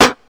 DrSnare19.wav